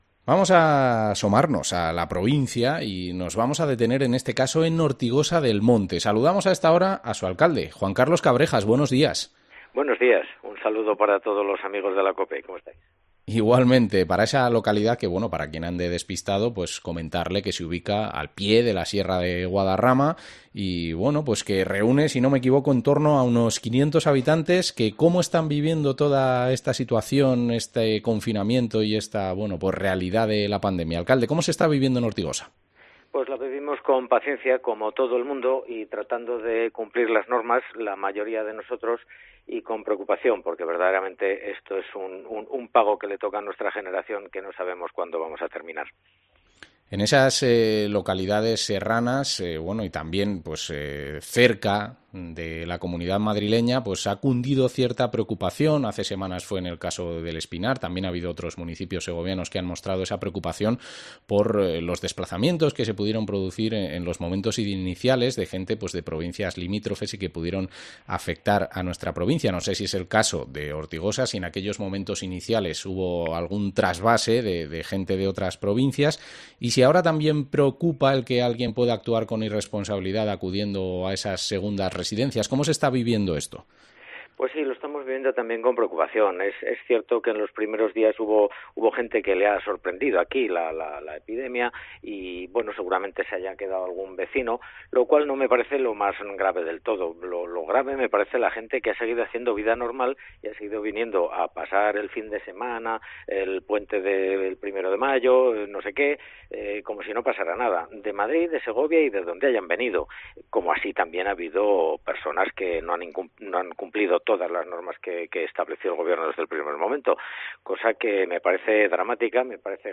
Entrevista al alcalde de Ortigosa del Monte, Juan Carlos Cabrejas